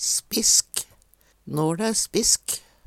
spisk - Numedalsmål (en-US)